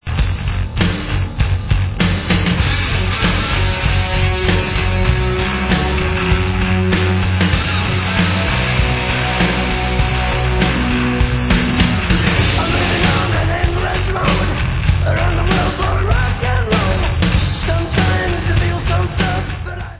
sledovat novinky v oddělení Heavy Metal